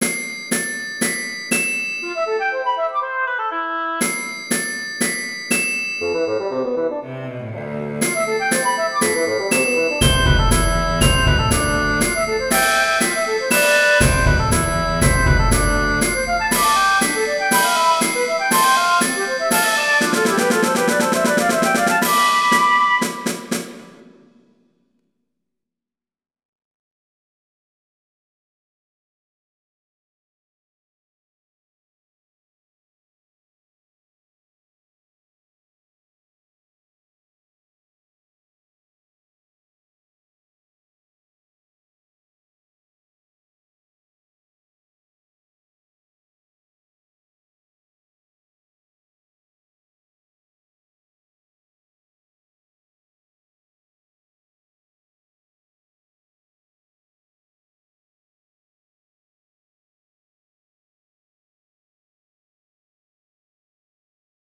(also please ignore how shitty the snare drum sounds)
That's pretty good and the snare only needs a little more reverb.